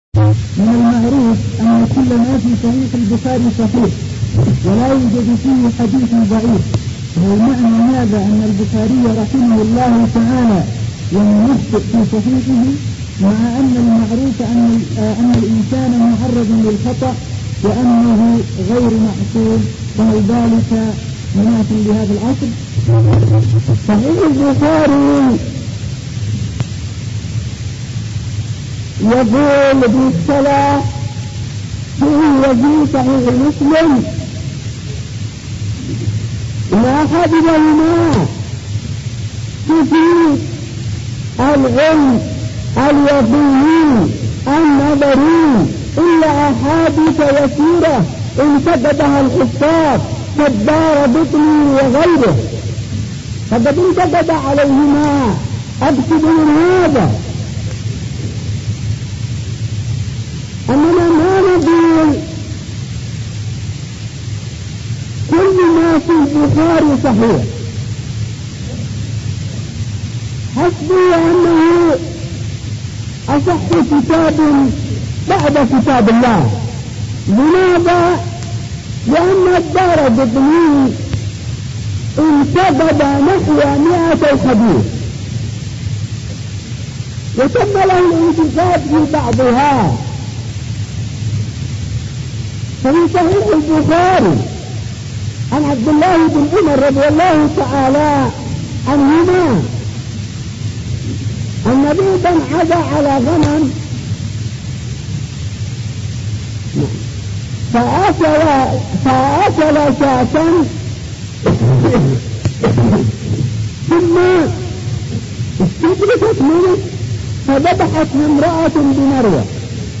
---------------- من شريط : ( أسئلة وأجوبة في صنعاء )